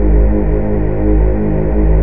gravity_lift.wav